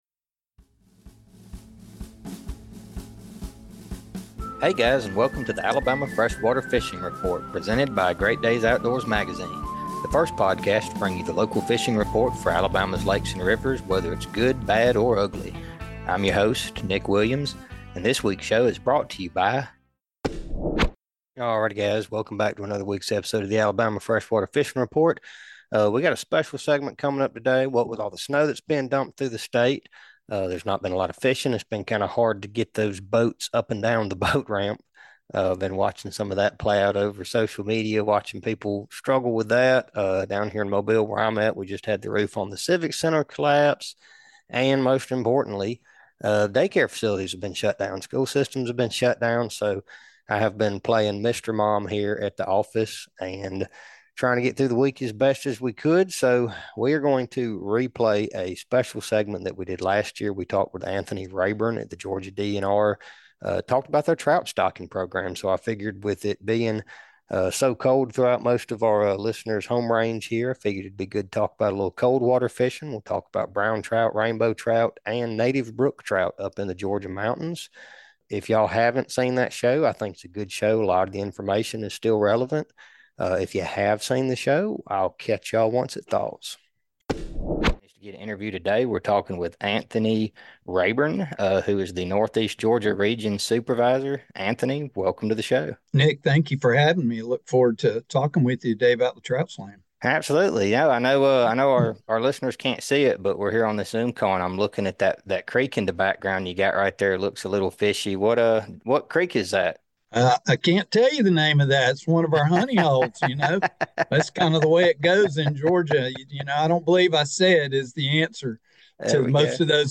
This is a special rebroadcast of the Alabama Freshwater Fishing Report. The report that is your best resource for the Lake Guntersville Fishing Report, Weiss Lake Fishing Report, Lake Eufaula Fishing Report, Mobile Tensaw Delta Fishing Report, and all the creeks, rivers, and reservoirs in between.